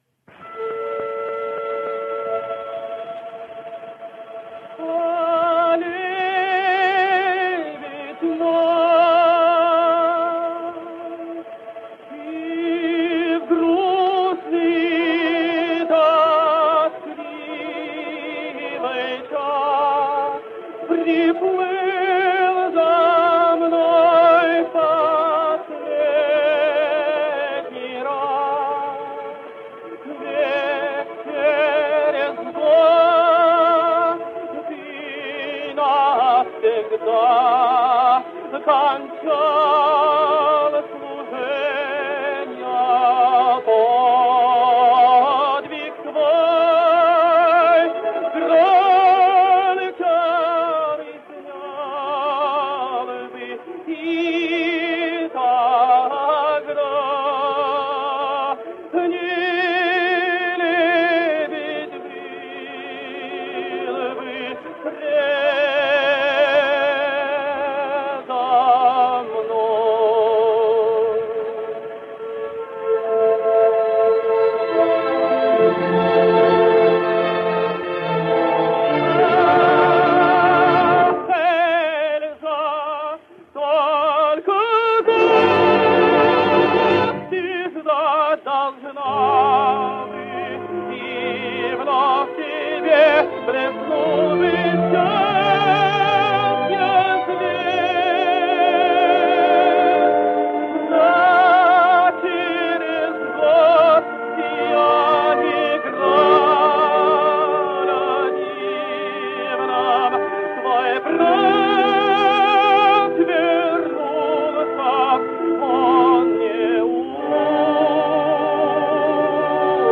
Giunto Sul Passo / Mefistofele / 1910 – Leonid Sobinov
I suppose you must be wondering how the electrically recorded orchestra we heard just now, got involved in a 1911 record.
That is to say, they had brought a modern orchestra into a studio and dubbed the voice from the original record onto the master, giving the illusion of the singer singing, to modern accompaniment.